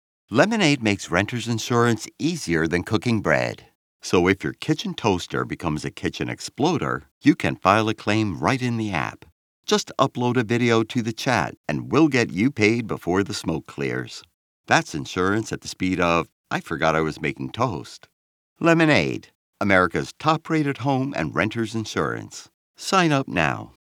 Male
English (North American)
Adult (30-50), Older Sound (50+)
Studio Quality Sample
Home Studio Commercial Read